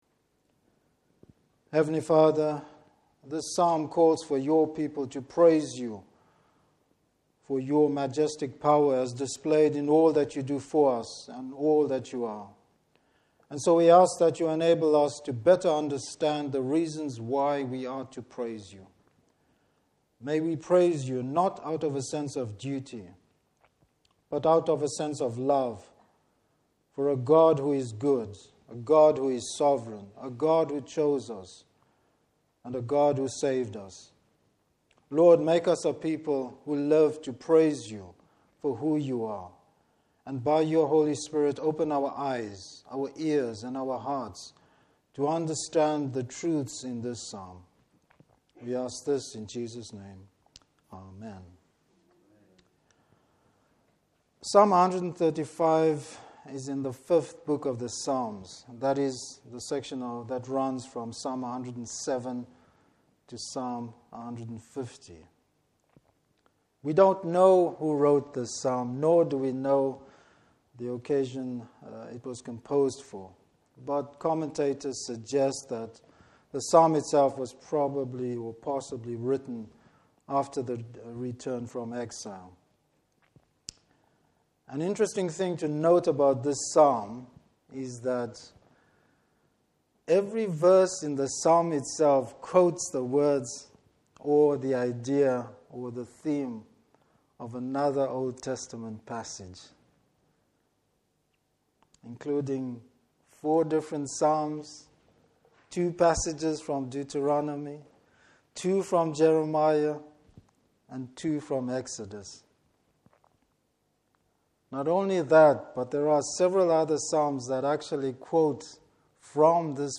Service Type: Evening Service The worthiness of God to receive our praise.